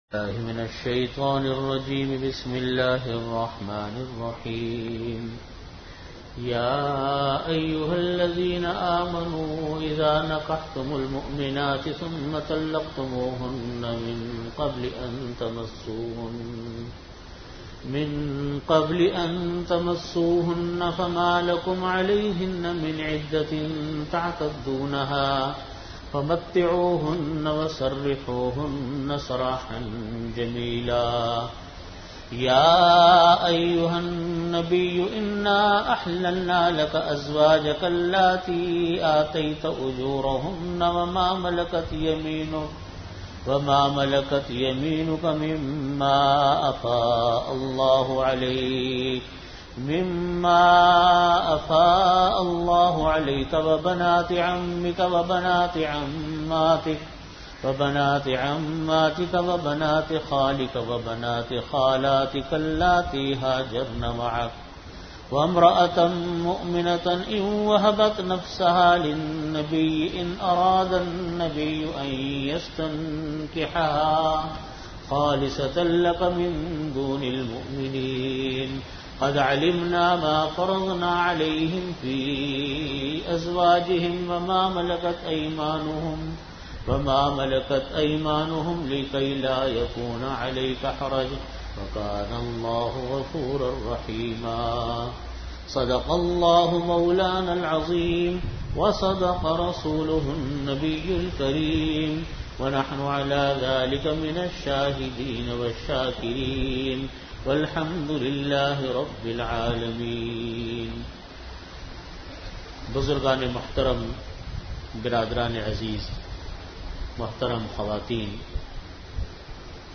Audio Category: Islahi Majlis-e-Zikr
Venue: Jamia Masjid Bait-ul-Mukkaram, Karachi